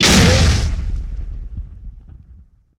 punch2.ogg